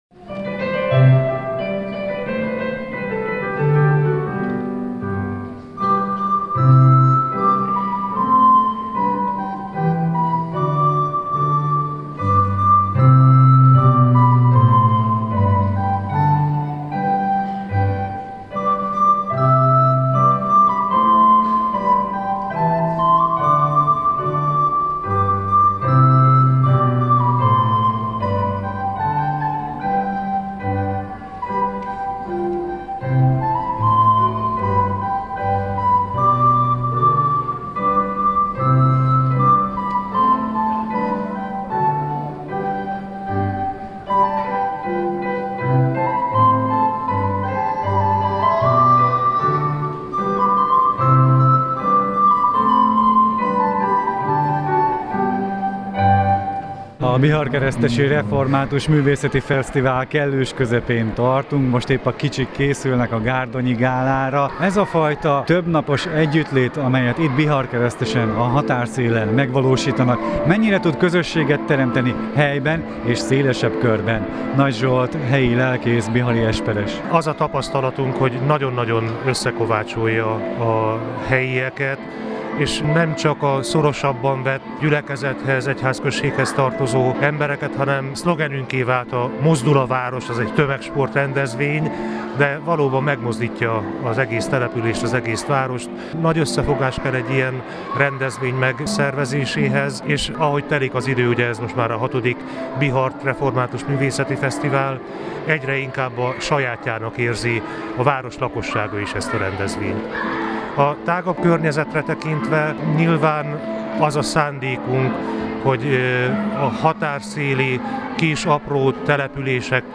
Az Európa Rádió a helyszínen járt.
bihart-fesztival.mp3